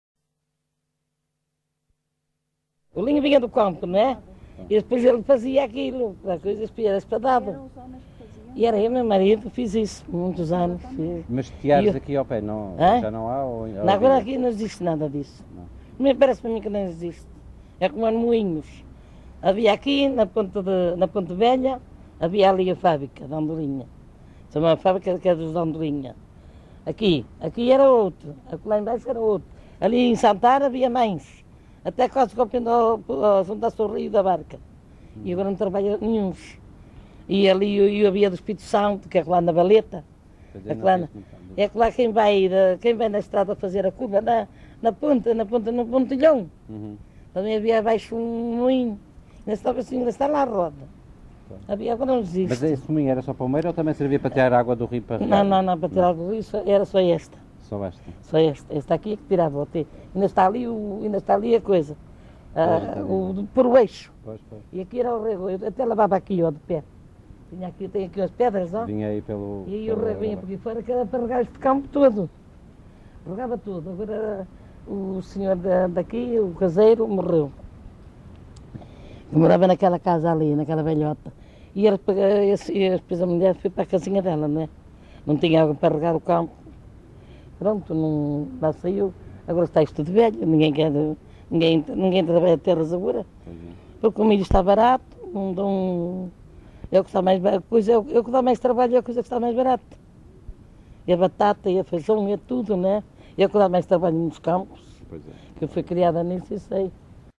Arcos de Valdevez, excerto 16
LocalidadeArcos de Valdevez (Arcos de Valdevez, Viana do Castelo)